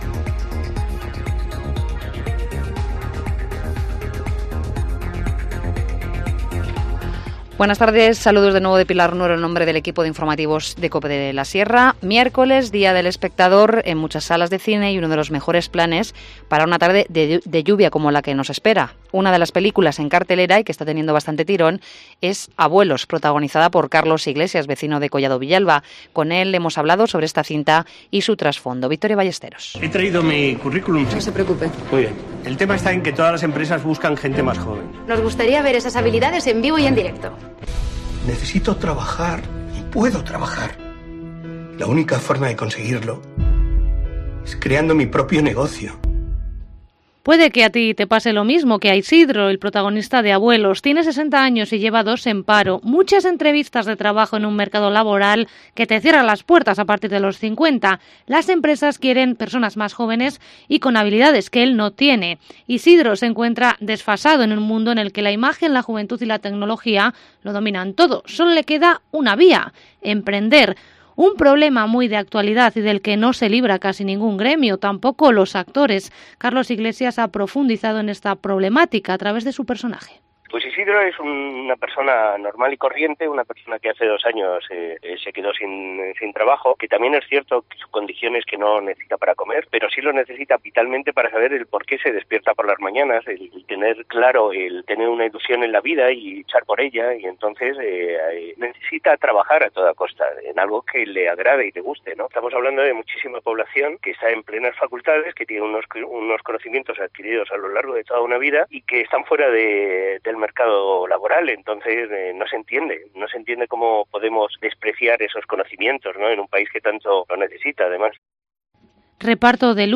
Informativo Mediodía 23 octubre 14:50h